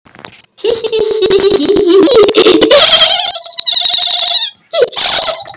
hehehe - Botão de Efeito Sonoro